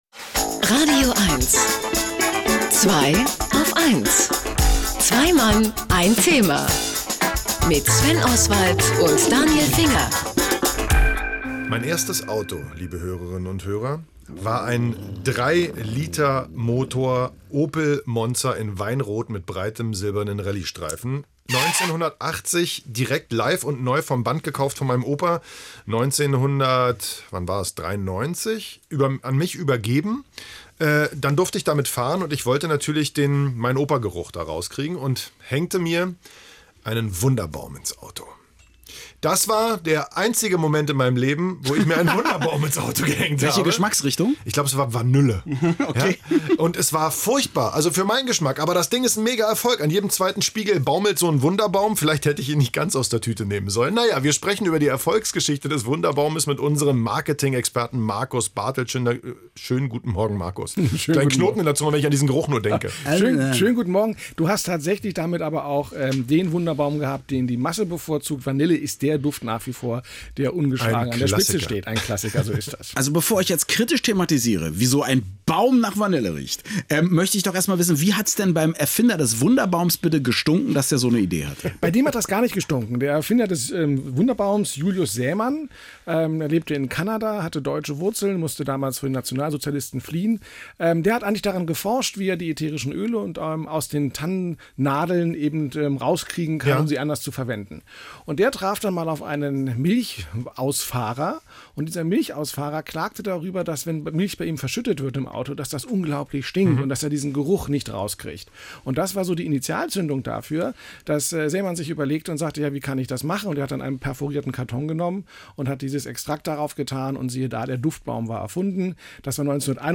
Und so sprach ich nun am Sonntag im radioeins-Studio über das Thema „Wunderbaum“: